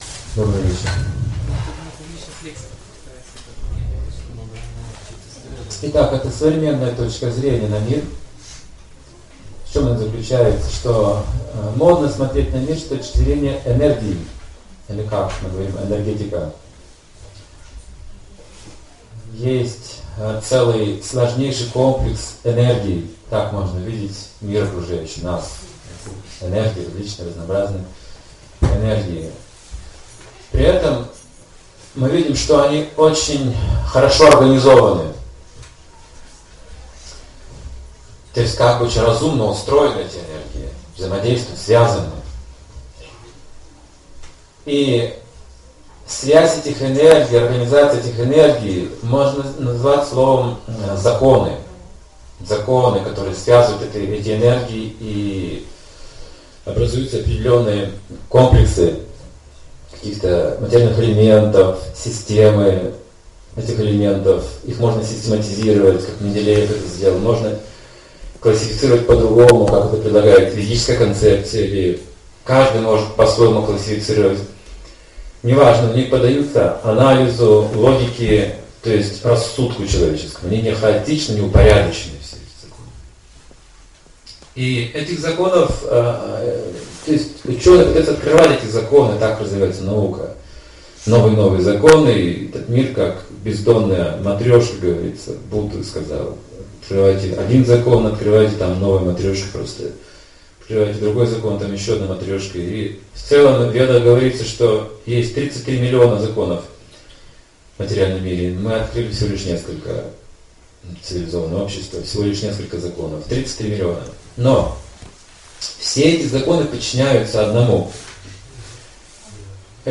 Лекция о законах природы и их аспектах. Рассматриваются энергии замкнутого цикла: создания, поддержания и разрушения.